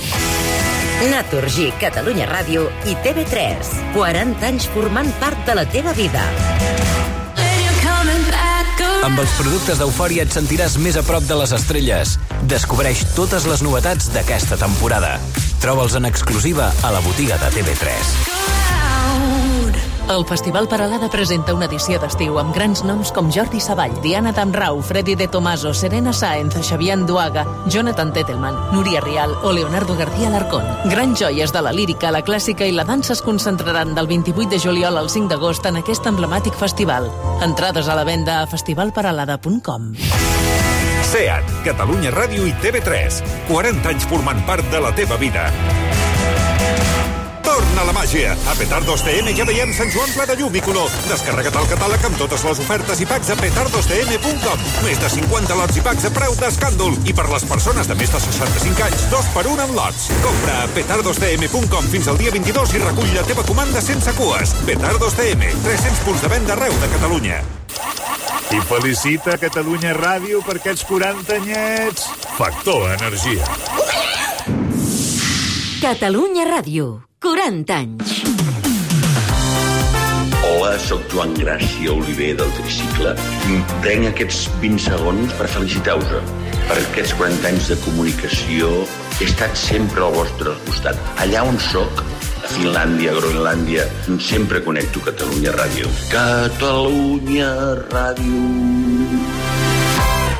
Publicitat, indicatiu dels 40 anys de l'emissora, salutació de Joan Gràcia del Tricicle
FM